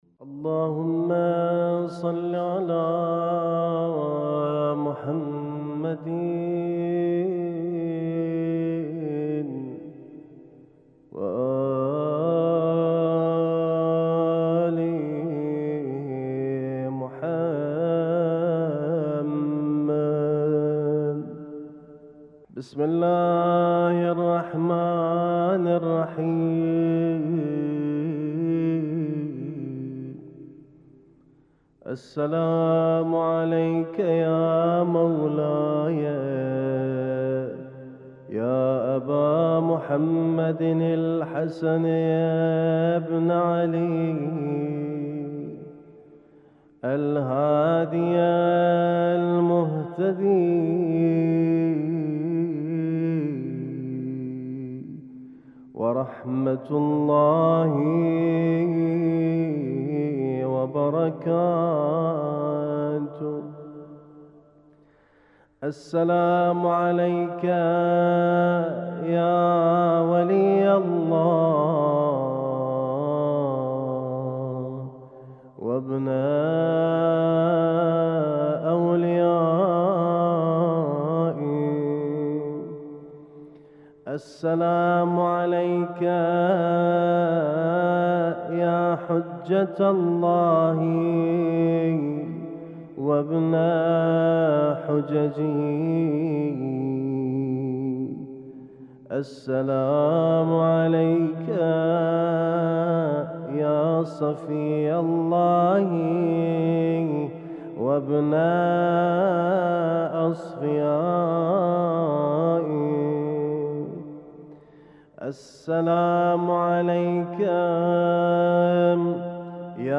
اسم التصنيف: المـكتبة الصــوتيه >> الزيارات >> الزيارات الخاصة